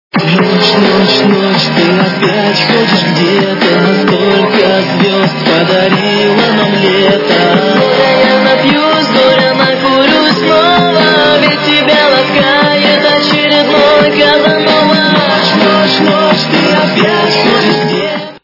русская эстрада
ремикс качество понижено и присутствуют гудки.